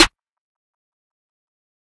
{Clap} Coachella.wav